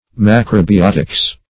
Macrobiotics \Mac`ro*bi*ot"ics\, n.
macrobiotics.mp3